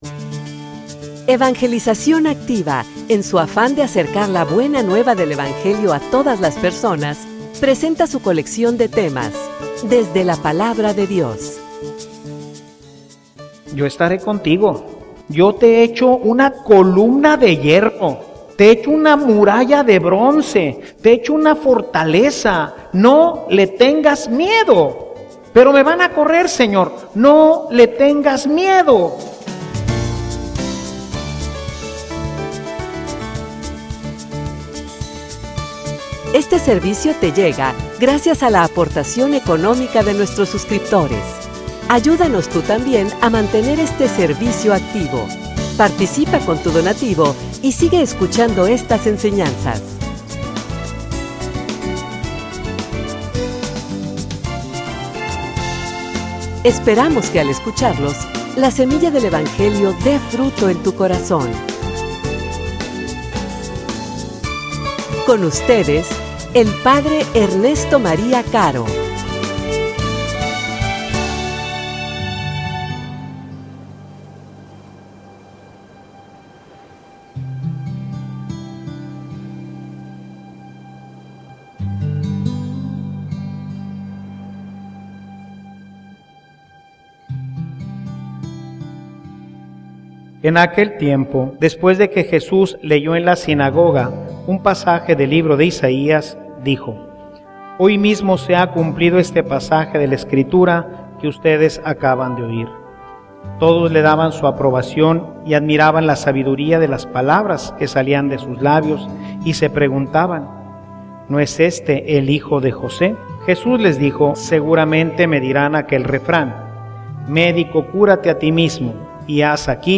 homilia_Yo_te_protegere.mp3